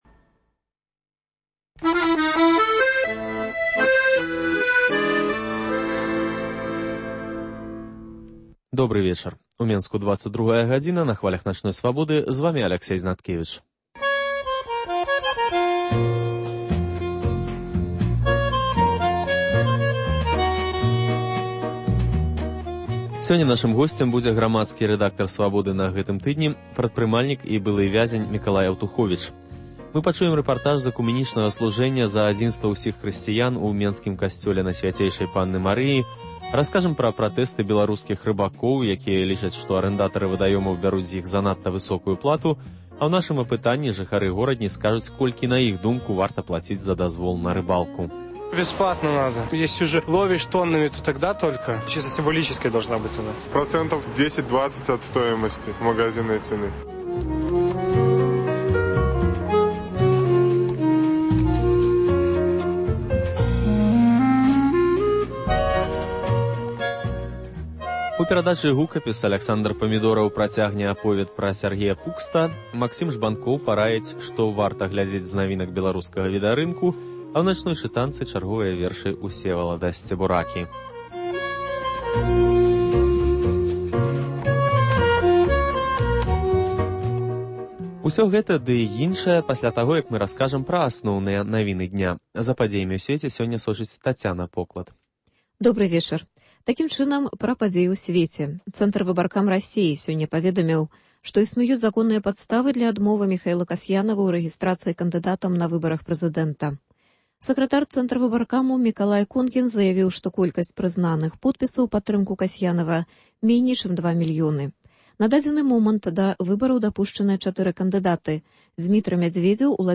Вы пачуеце рэпартаж з экумэнічнага служэньня за адзінства ўсіх хрысьціян у менскім касьцёле Найсьвяцейшай Панны Марыі.